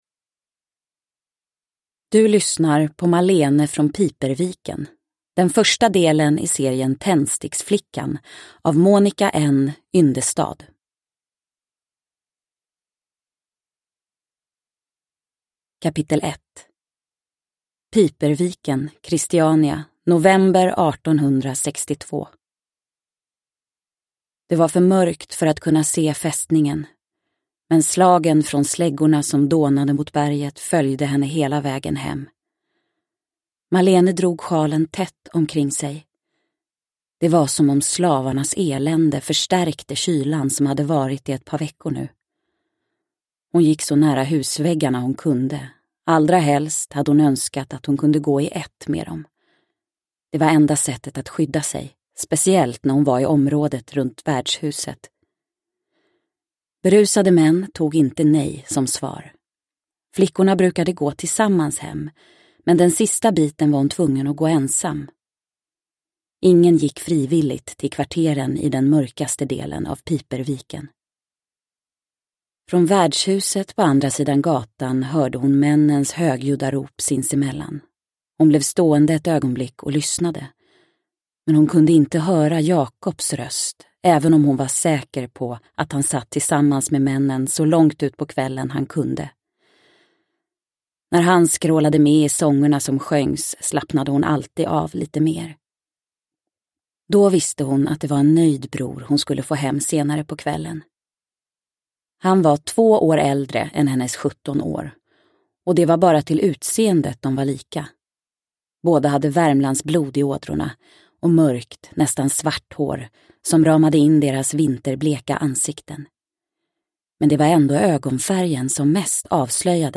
Malene från Piperviken – Ljudbok – Laddas ner